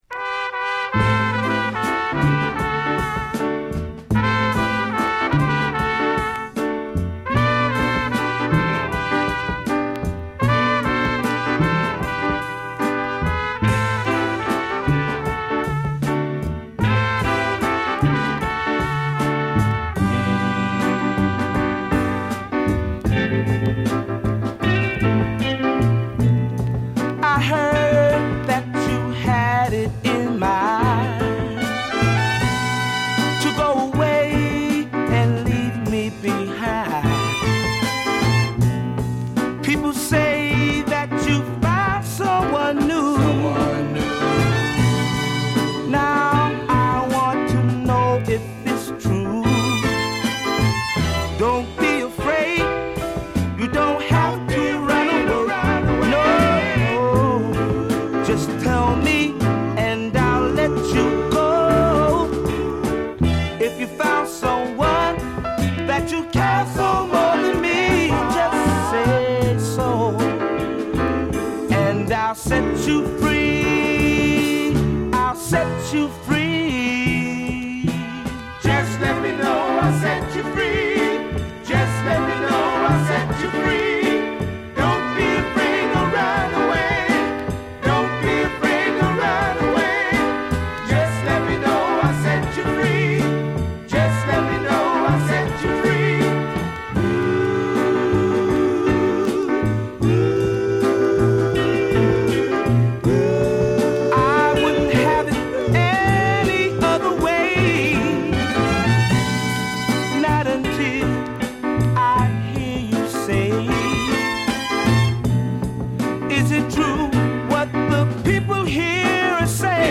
slower soulful numbers